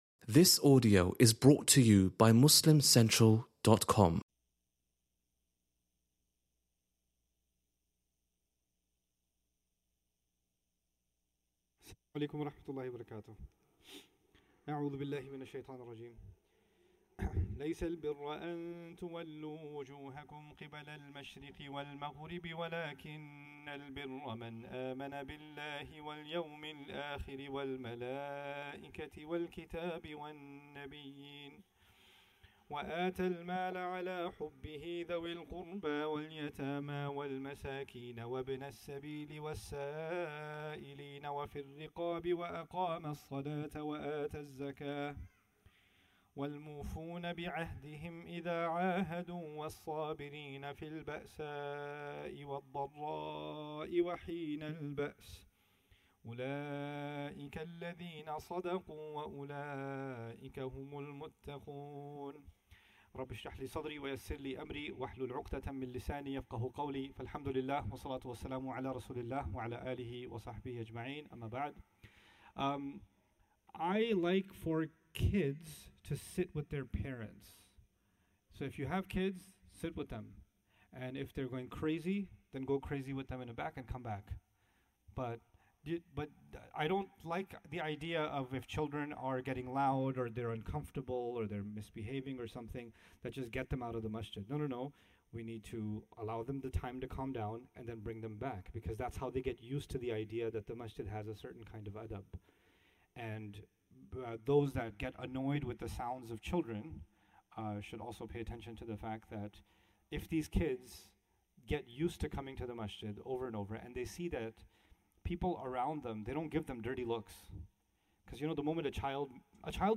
Live Halaqah